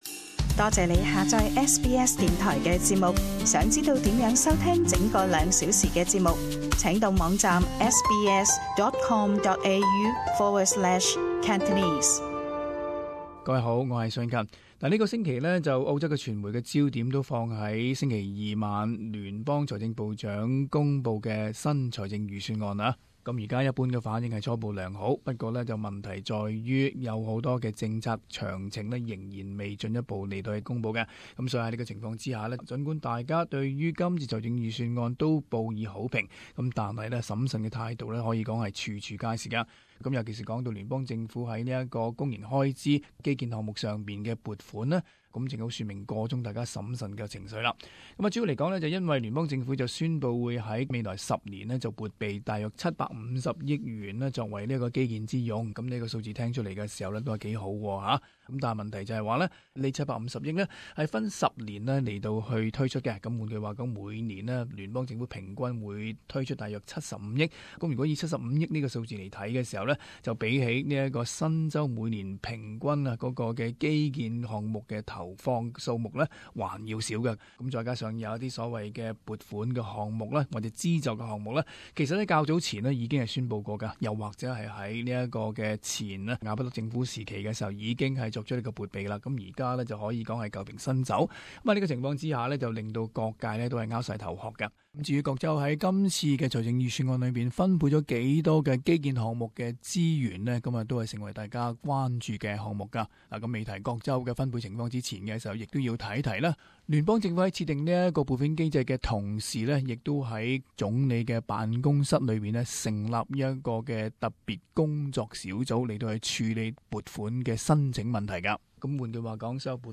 【时事报导】各州政府对预算案基建拨款的不同反应